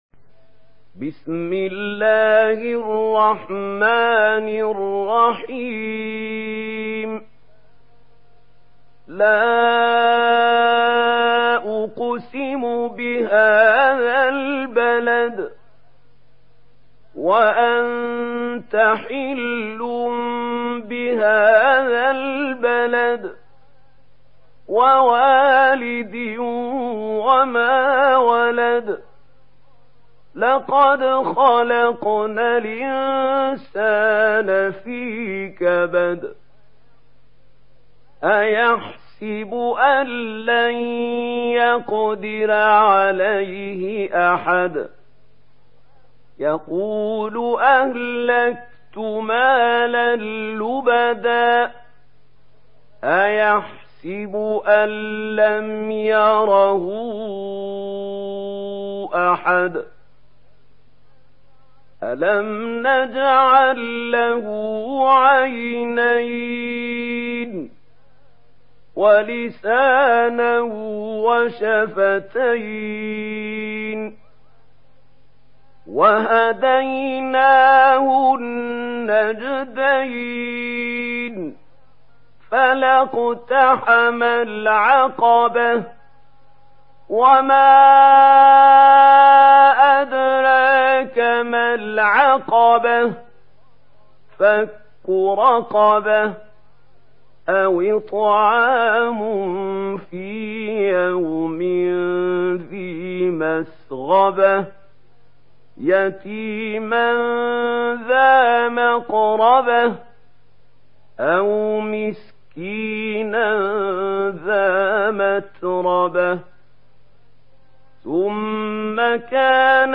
Surah আল-বালাদ MP3 in the Voice of Mahmoud Khalil Al-Hussary in Warsh Narration
Murattal Warsh An Nafi